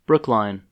Brookline (/ˈbrʊkln/
En-us-Brookline.oga.mp3